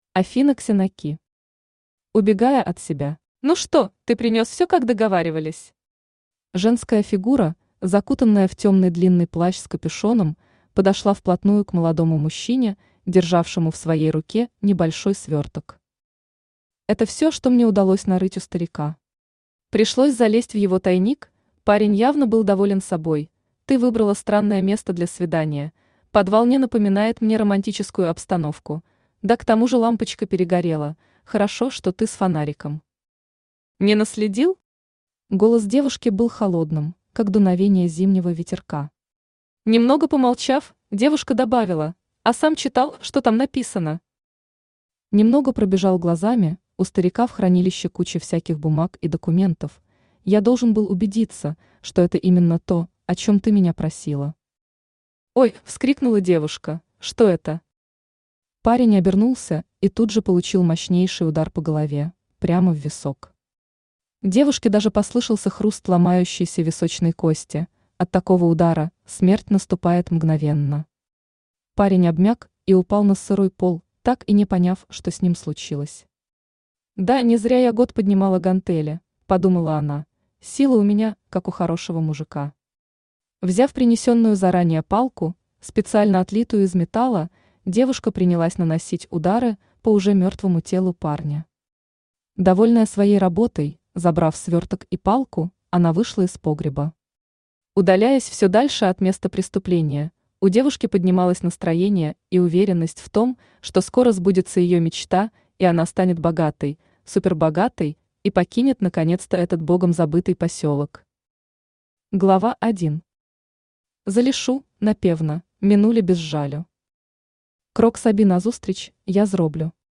Аудиокнига Убегая от себя | Библиотека аудиокниг
Aудиокнига Убегая от себя Автор Афина Ксенаки Читает аудиокнигу Авточтец ЛитРес.